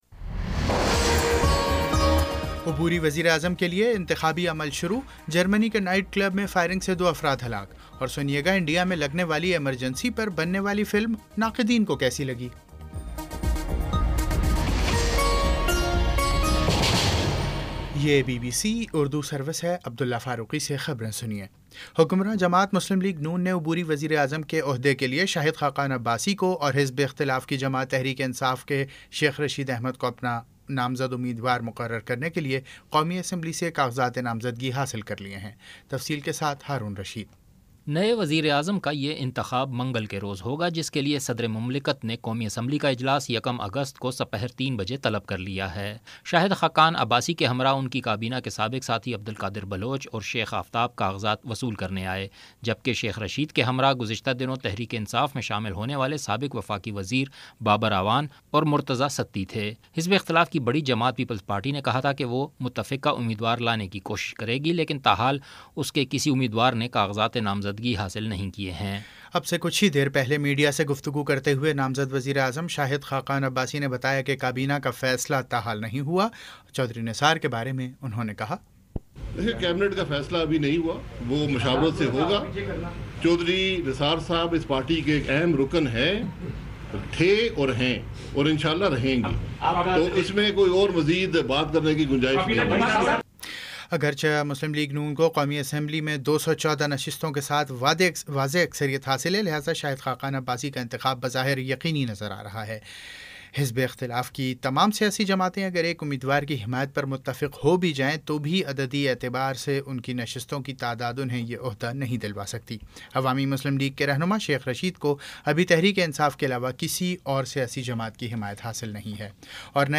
جولائی 30 : شام پانچ بجے کا نیوز بُلیٹن